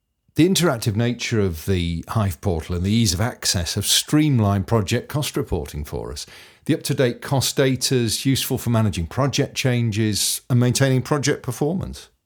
Male
English (British)
Adult (30-50), Older Sound (50+)
Reassuring, authoritative, calm, melodious, deep, male, British
Main Demo